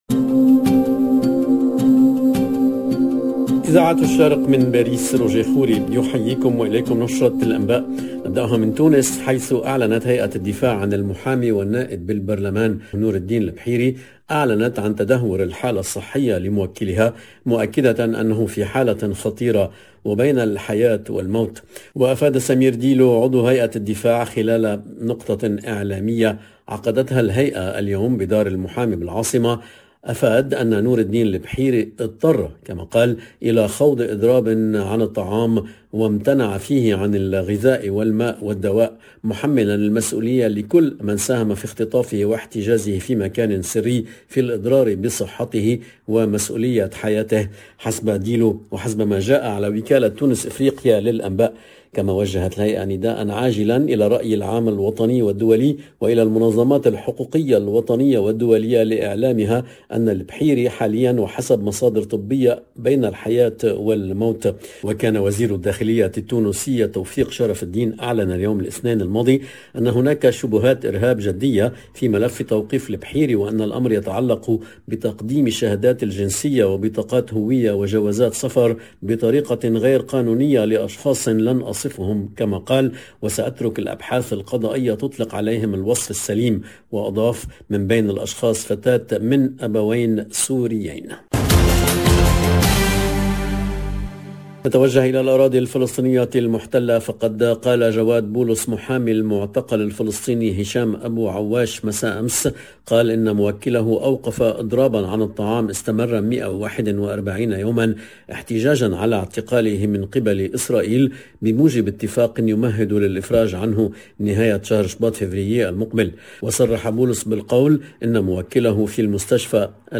LE JOURNAL DU SOIR EN LANGUE ARABE DU 05/01/22